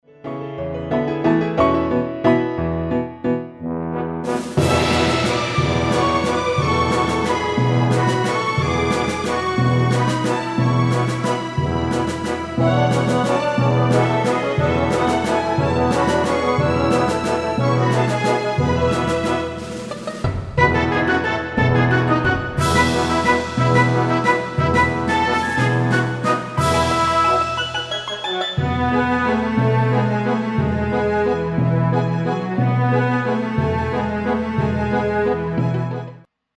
Музыка для театра